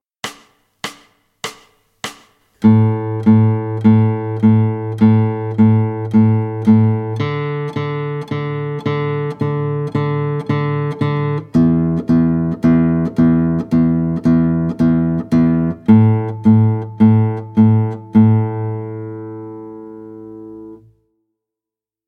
Guitare Classique